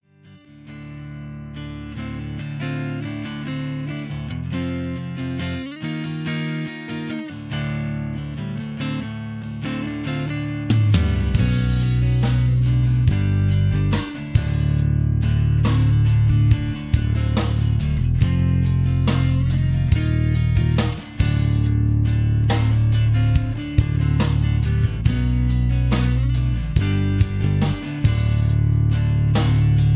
backing tracks
rock